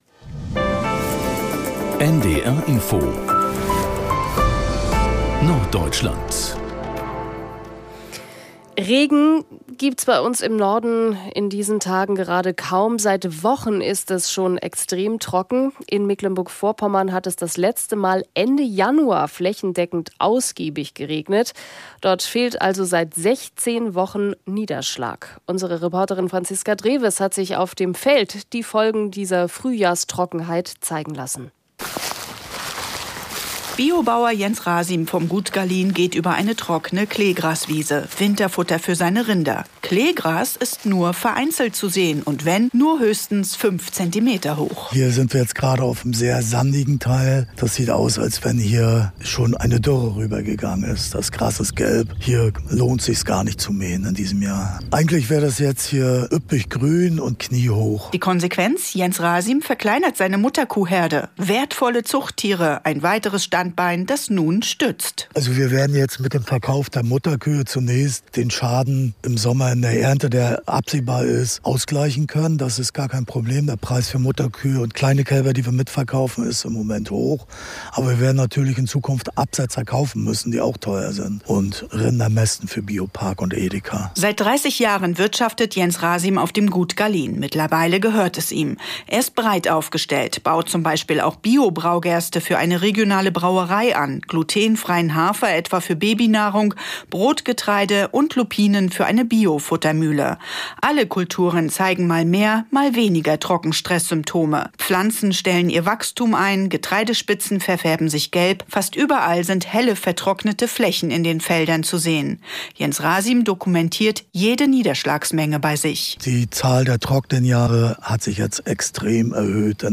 Nachrichten aus Norddeutschland